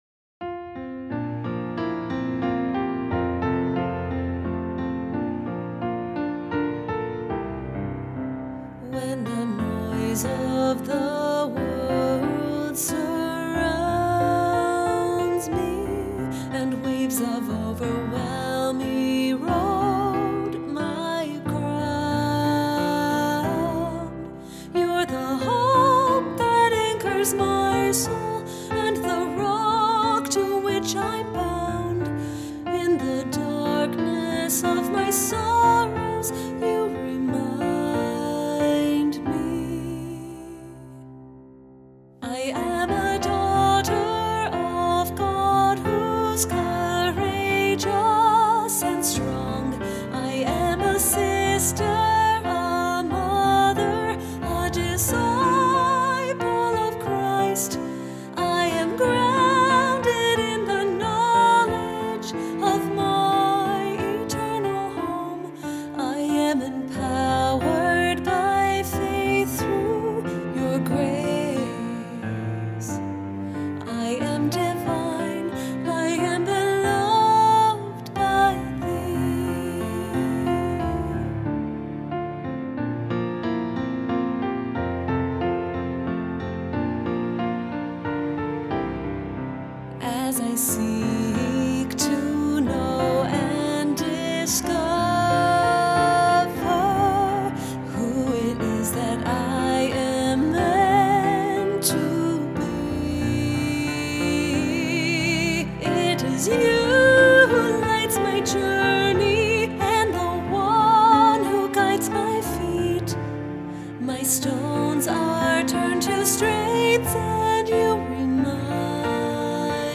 Vocal Solo
Medium Voice/Low Voice